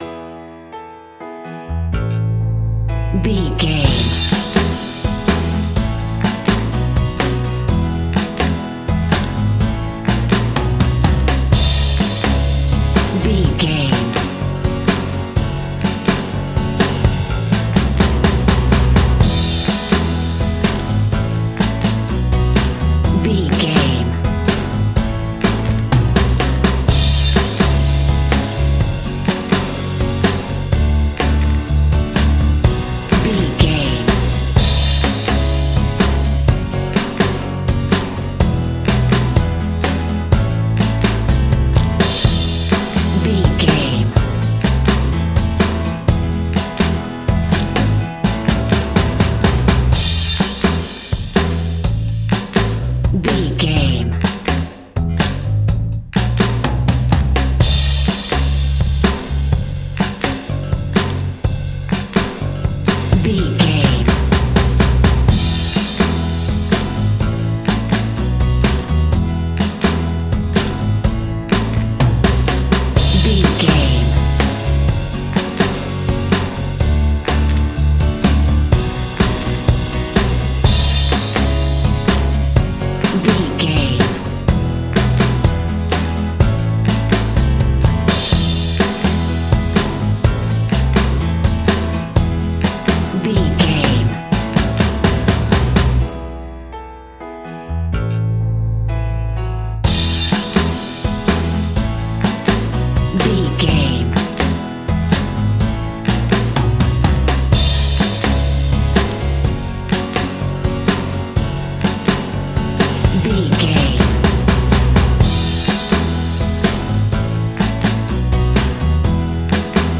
Ionian/Major
60s
fun
energetic
uplifting
cheesy
acoustic guitars
drums
bass guitar
electric guitar
piano
electric piano
organ